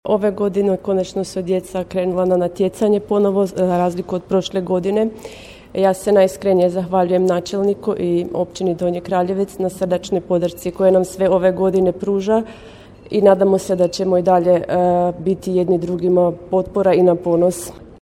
Državne prvakinje i doprvakinje, ali i europske viceprvakinje na prijemu kod načelnika